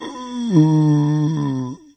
Index of /server/sound/npc/firezombie